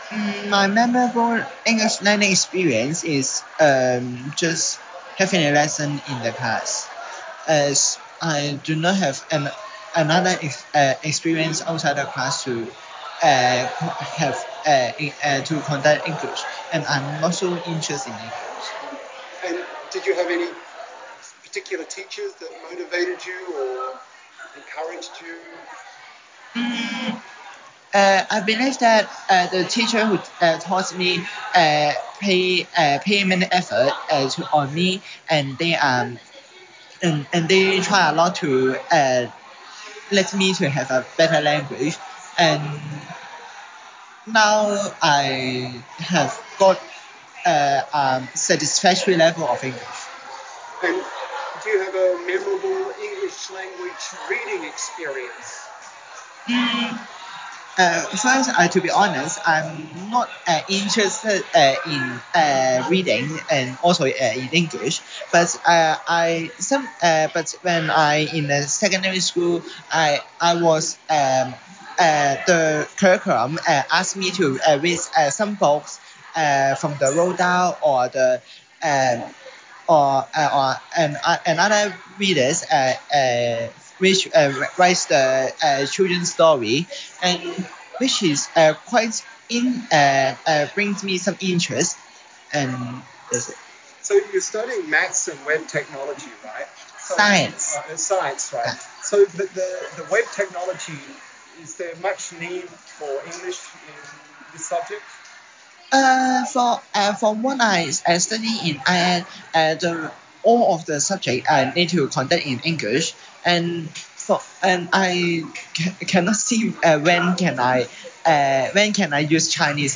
A student credits his teachers for helping him reach what he calls a satisfactory level of English. He remembers reading Roald Dahl because he had to.